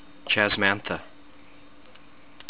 chas-MAN-the